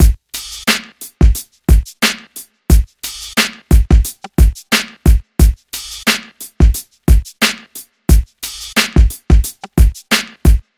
鼓声 89 Bpm
描述：新的鼓声，让经典的嘻哈成为现实
Tag: 89 bpm Hip Hop Loops Drum Loops 1.81 MB wav Key : Unknown FL Studio